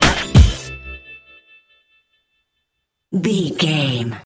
Aeolian/Minor
drum machine
synthesiser
percussion
neo soul
acid jazz
energetic
cheerful
bouncy
Triumphant